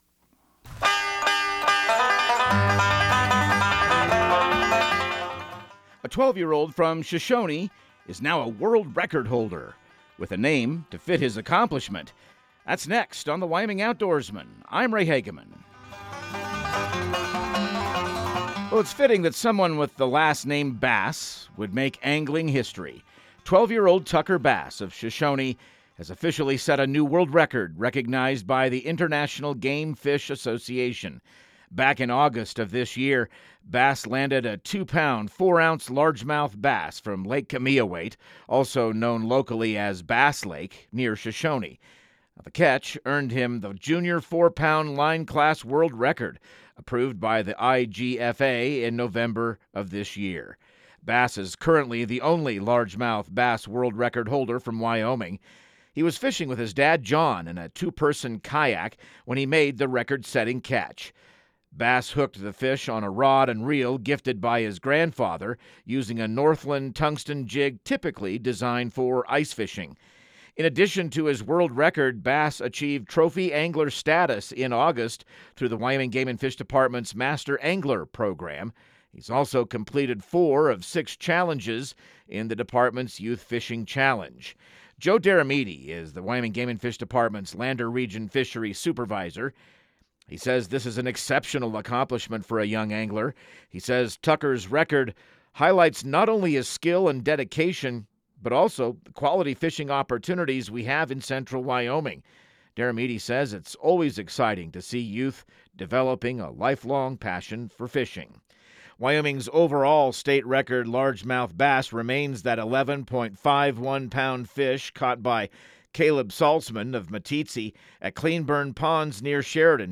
Radio news | Week of December 22